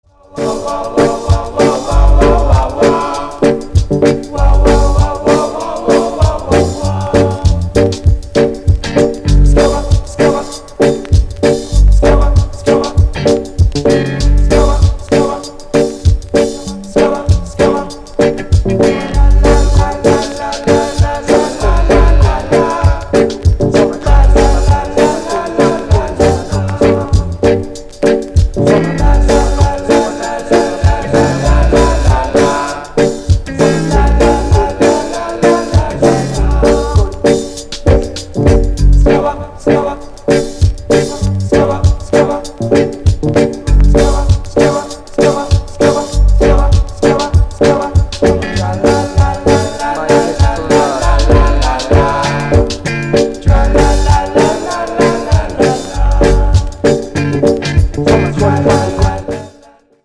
dub comp